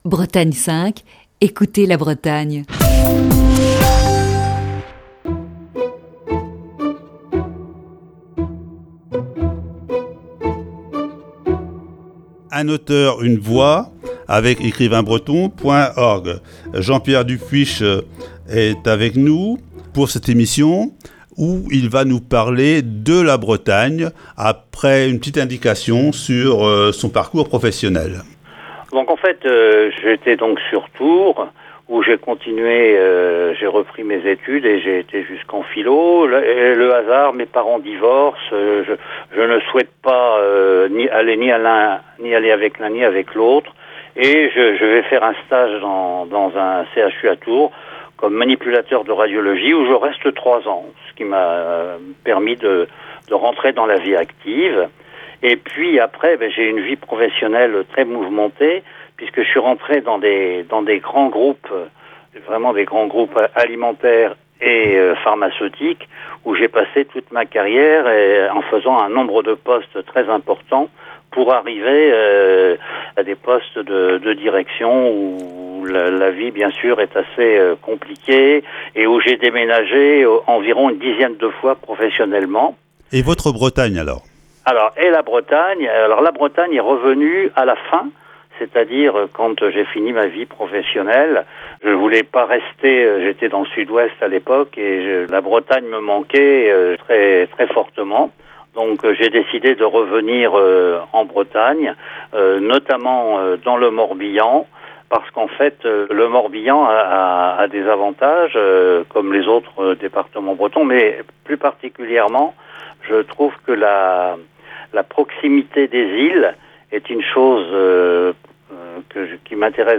Chronique du 16 juin 2020.
entretien